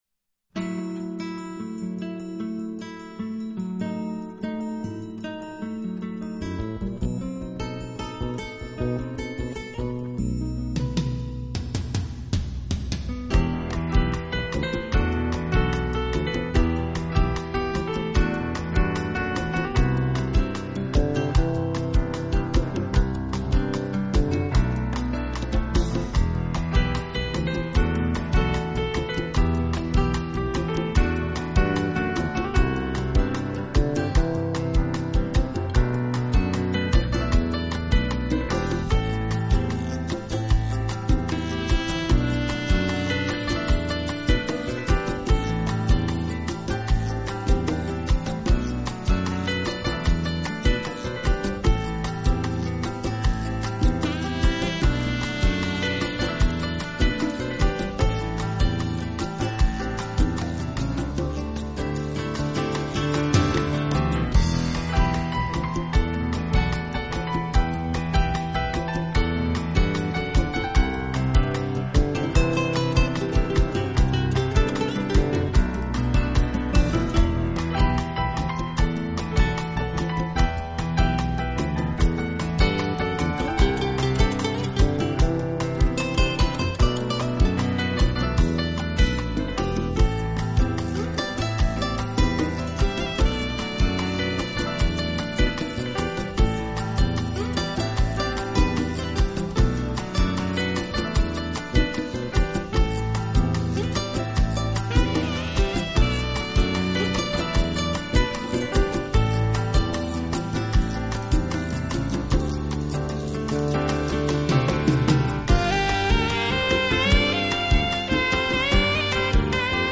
其标志性的特点是在自然音阶中揉入打击乐和电子音乐，再加上吉它、电贝斯、
长笛和萨克斯管演奏。
新世纪纯音乐